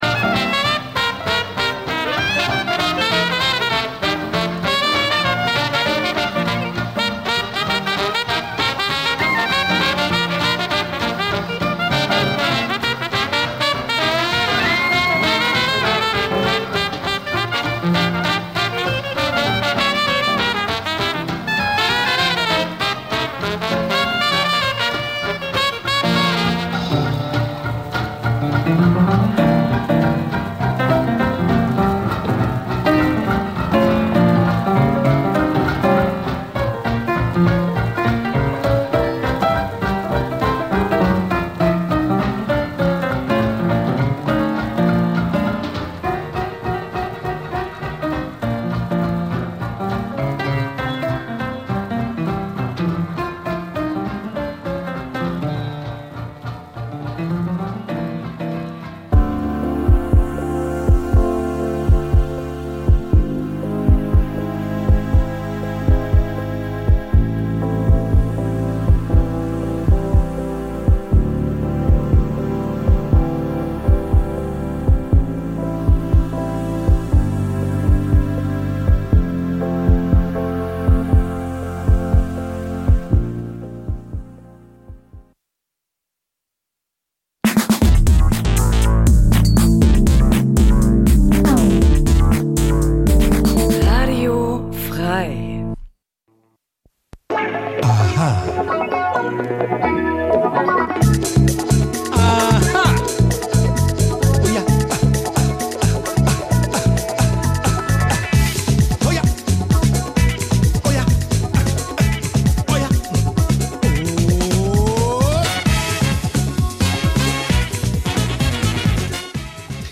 Afrikanisches zweisprachiges Magazin Dein Browser kann kein HTML5-Audio.
Die Gespräche werden mit afrikanischer Musik begleitet.